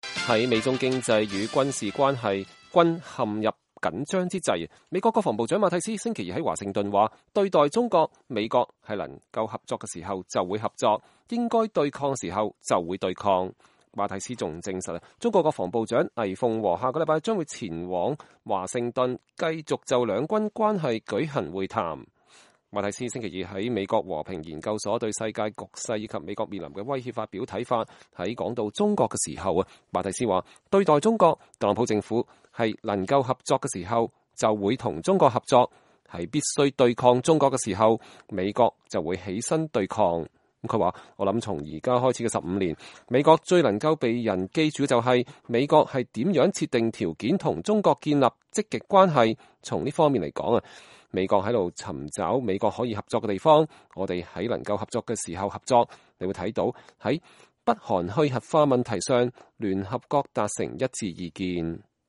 馬蒂斯2018年10月30日在美國和平研究所講話（美國國防部視頻截圖）
馬蒂斯星期二在美國和平研究所對世界局勢以及美國面臨的威脅發表看法。